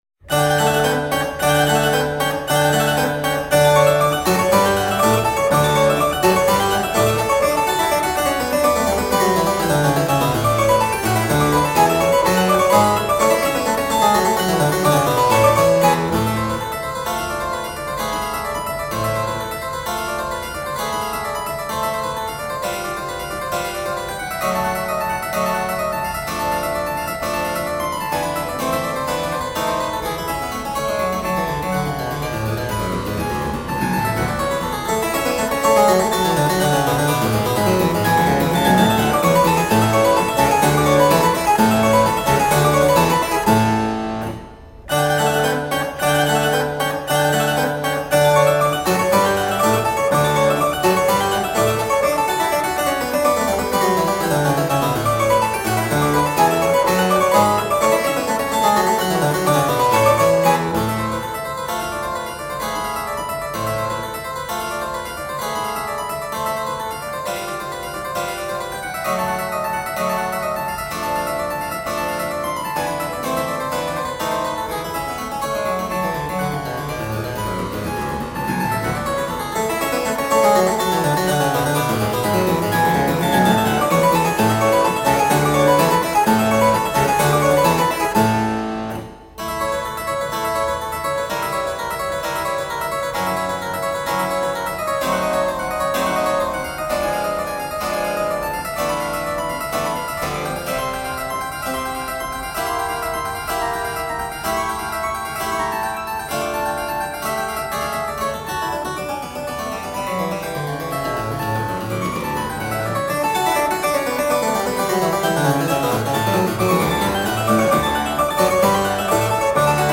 Sonate pour clavecin Kk 511 : Presto